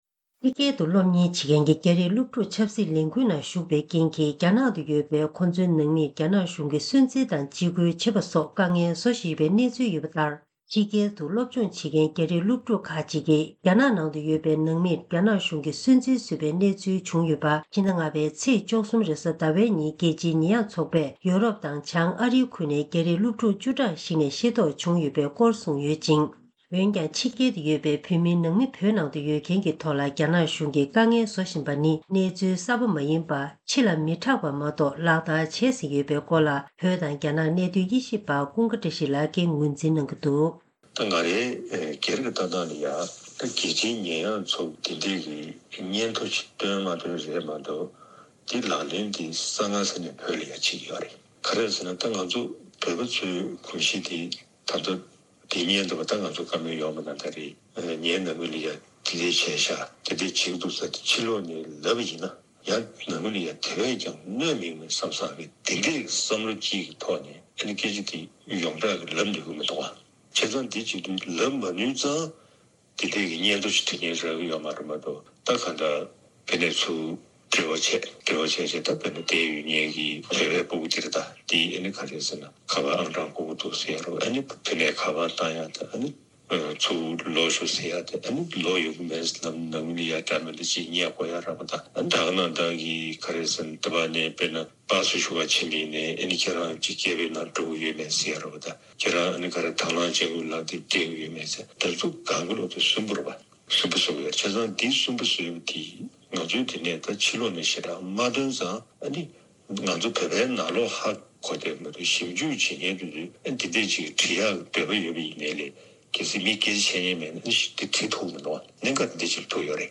སྒྲ་ལྡན་གསར་འགྱུར། སྒྲ་ཕབ་ལེན།
ཐེངས་འདིའི་གསར་འགྱུར་དཔྱད་གཏམ་གྱི་ལེ་ཚན་ནང་།